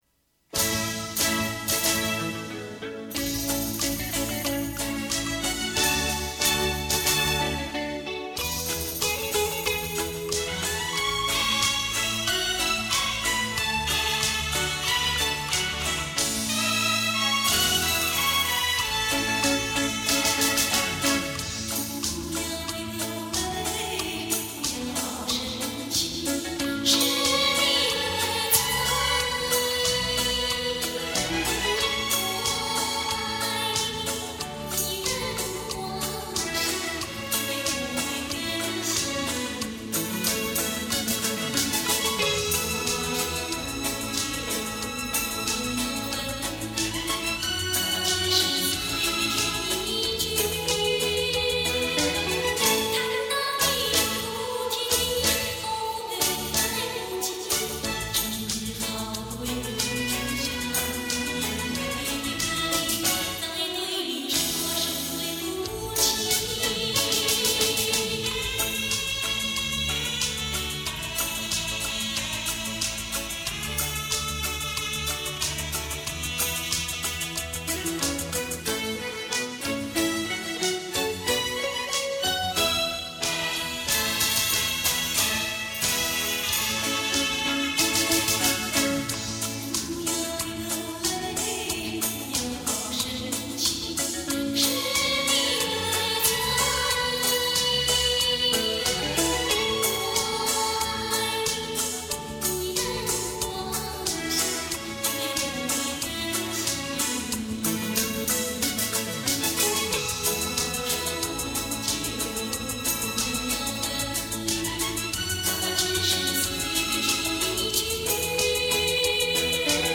小调　小曲类型的歌曲。